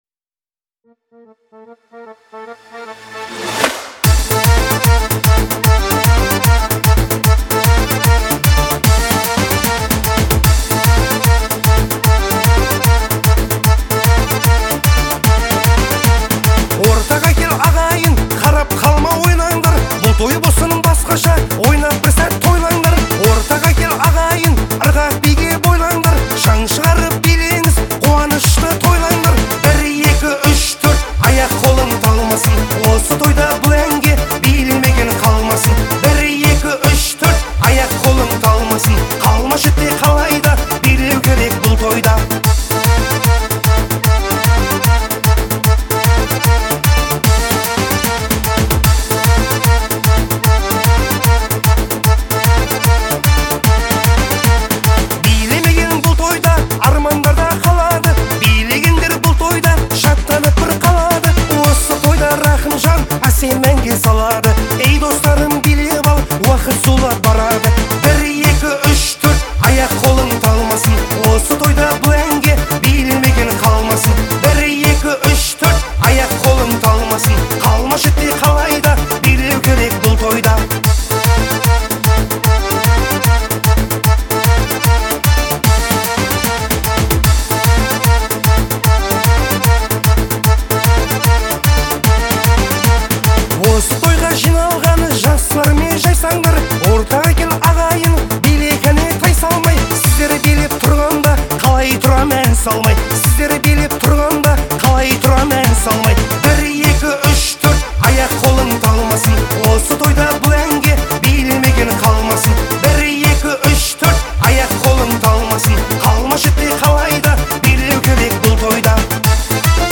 яркая и энергичная композиция